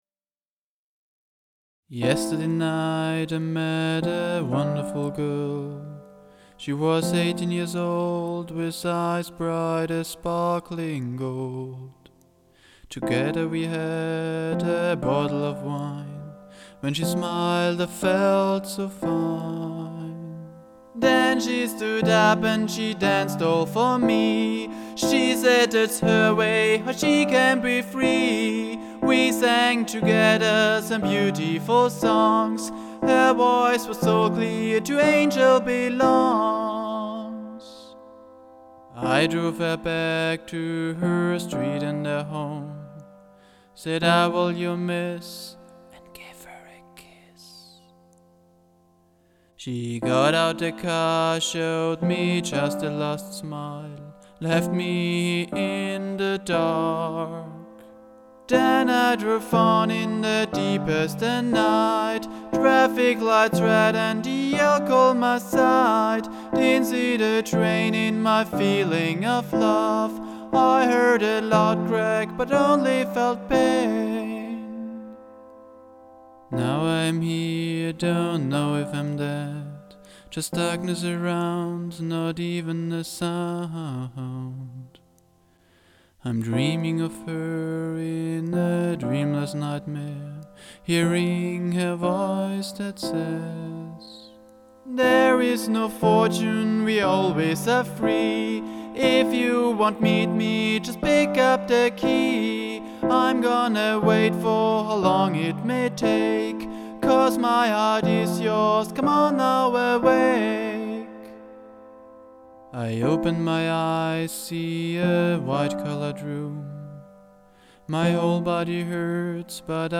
| ein melodramatisches Liebeslied, am Klavier begleitet
loveballad_final_mp3.mp3